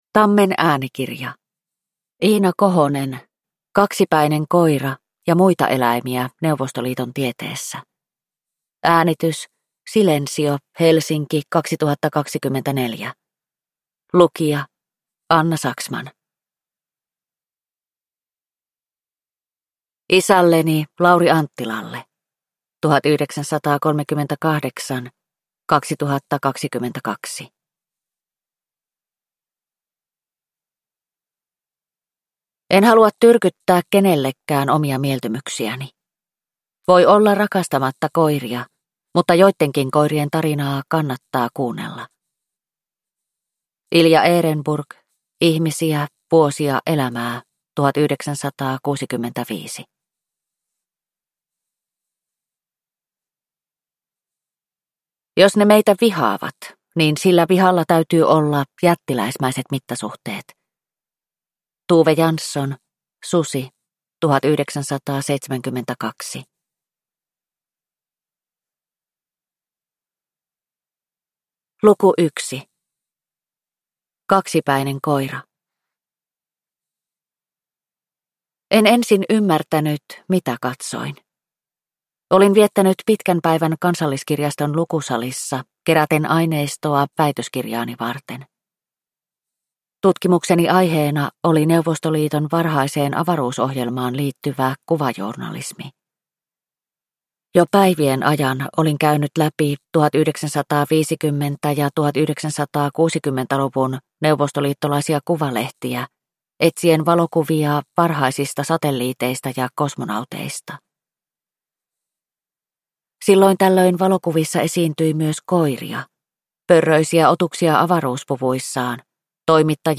Kaksipäinen koira – Ljudbok